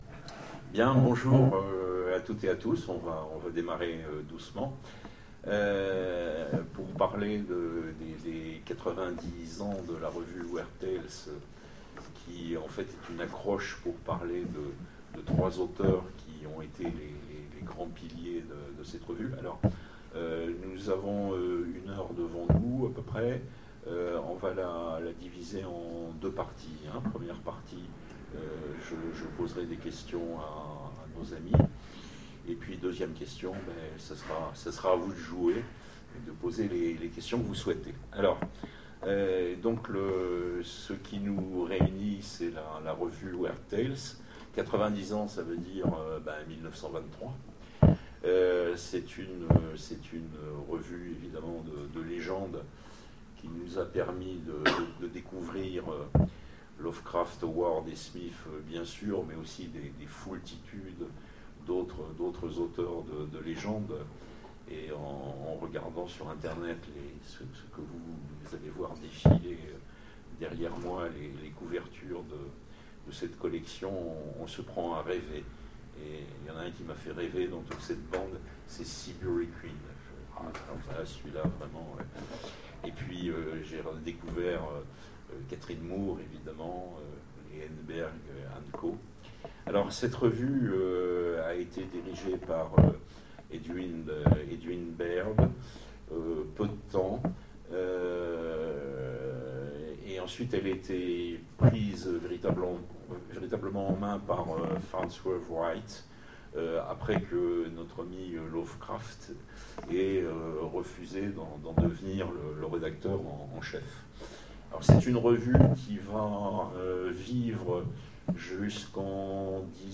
Sèvres 2013 : Conférence 90 ans de Weird Tales, HPL, REH, CAS